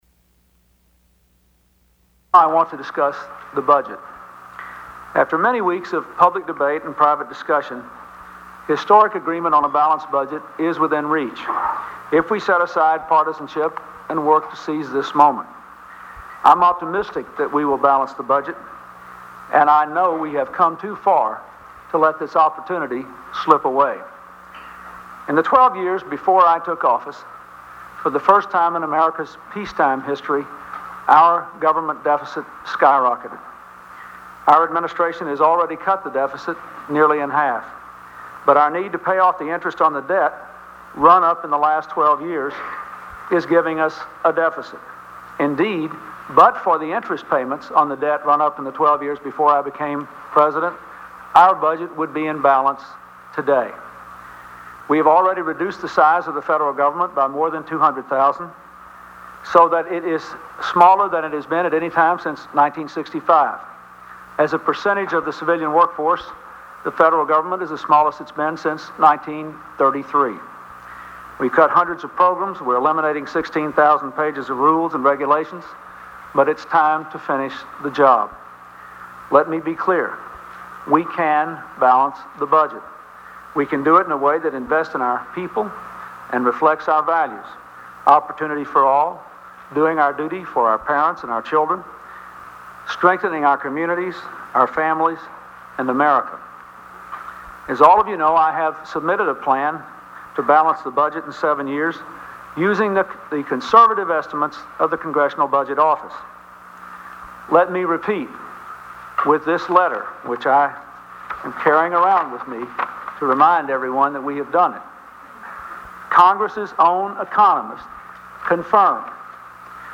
U.S. President Bill Clinton's first press conference in five months deals with balancing the budget